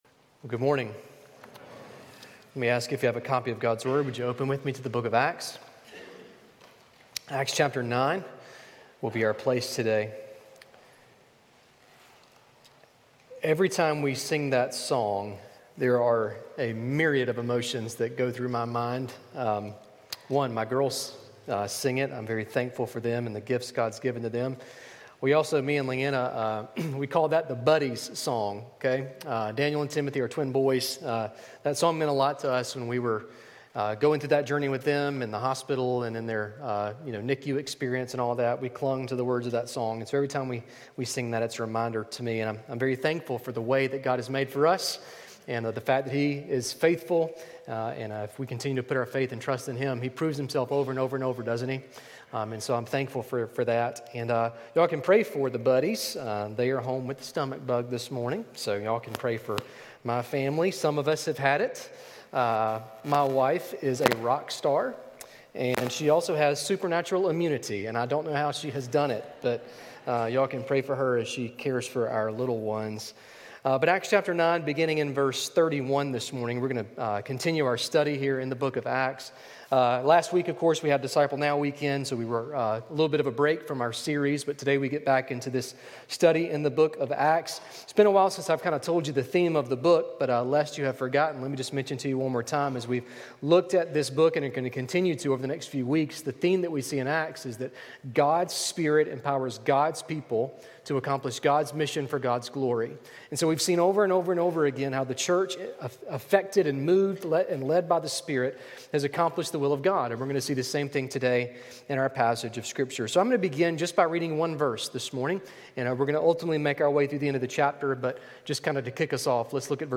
Sermon Listen Service Scripture References